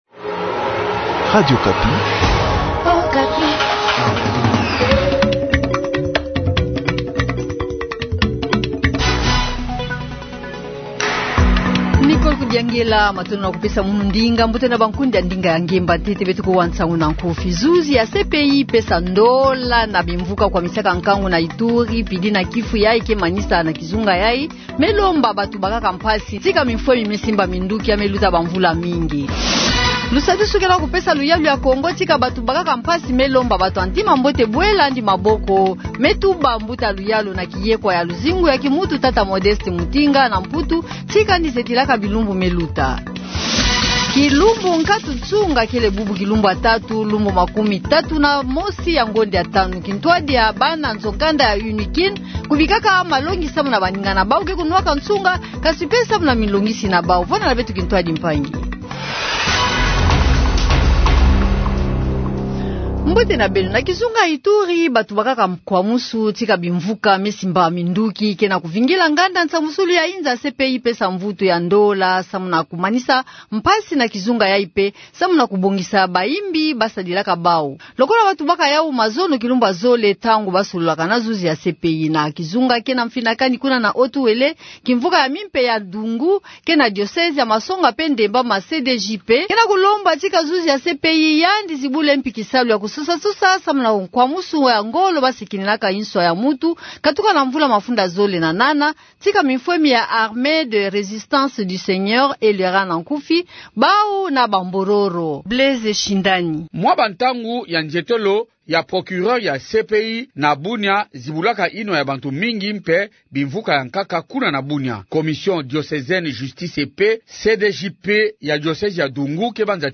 Journal du soir
• Kinshasa : Journée sans tabac, reportage à l’UNIKIN.